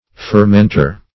Fermenter \Fer*ment"er\, n.